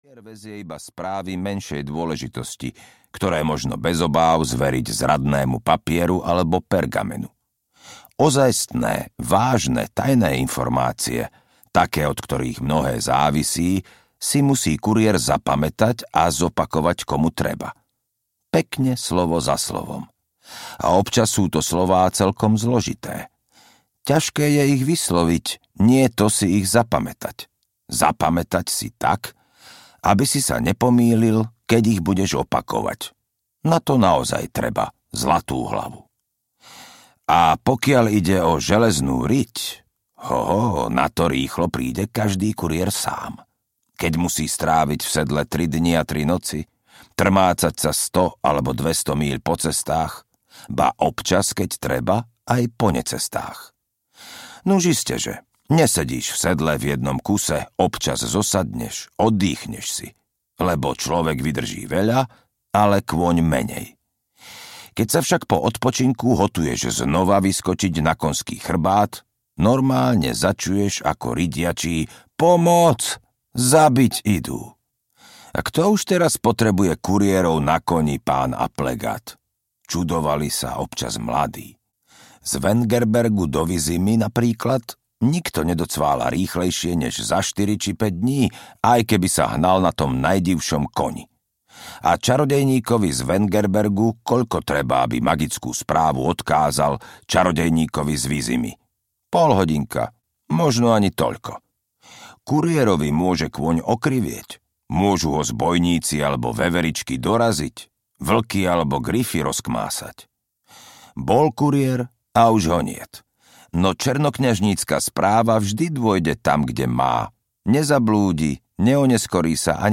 Zaklínač IV: Čas opovrhnutia audiokniha
Ukázka z knihy
zaklinac-iv-cas-opovrhnutia-audiokniha